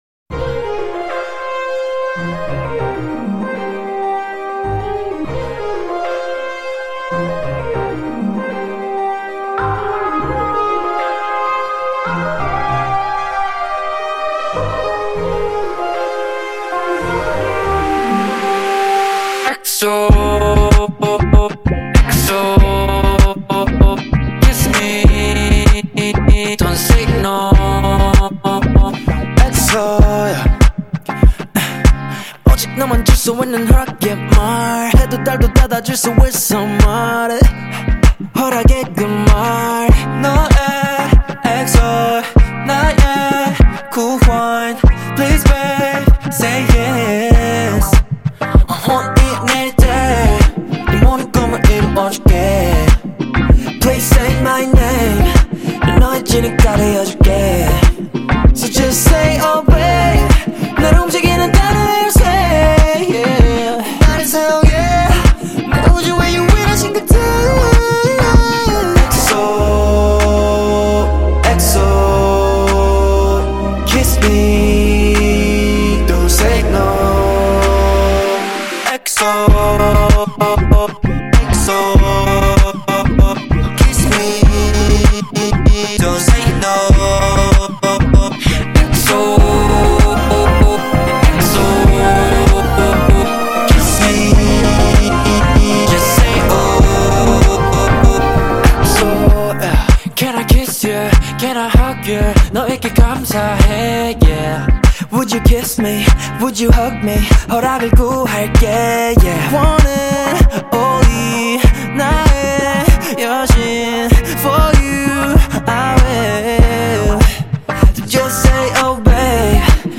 Label Dance